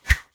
Close Combat Swing Sound 54.wav